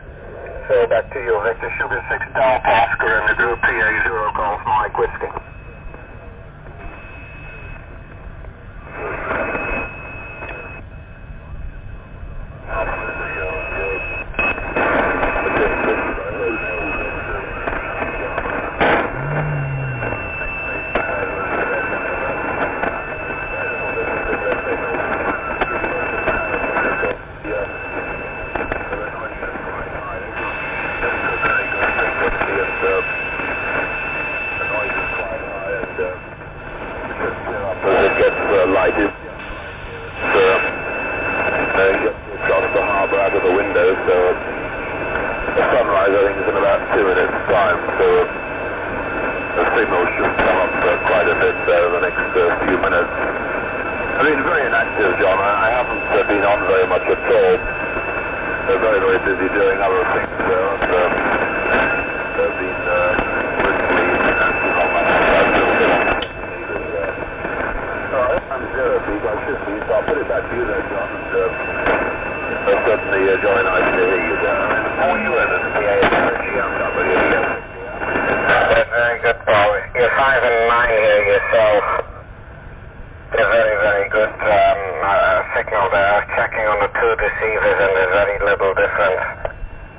80M 3800kHz
The affect of Greyline propagation clearly heard in this recording from 1976, at sunrise in Hong Kong.